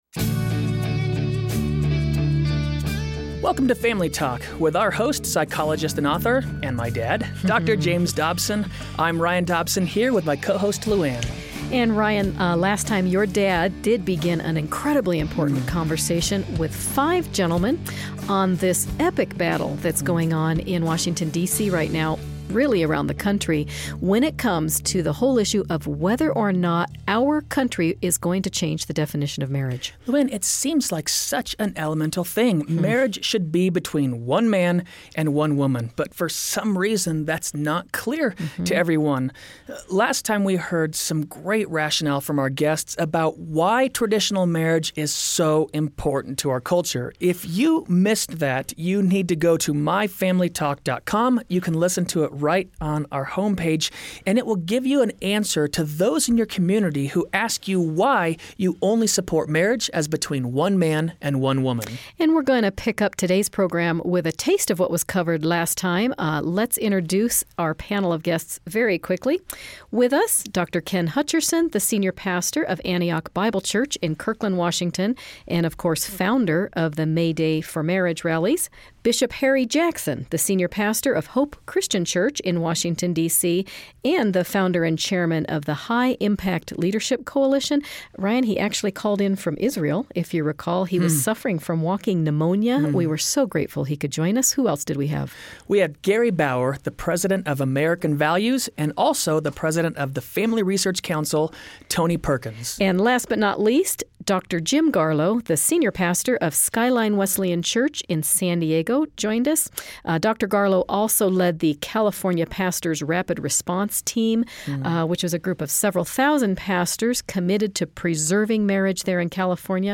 When President Obama recently announced that the federal government would not legally defend marriage as being between one man and one woman, it created a firestorm of controversy and was especially disappointing to those who maintain a Judeo-Christian worldview. On today's program, Dr. Dobson and a panel of experts discuss how the President's decision will impact our society.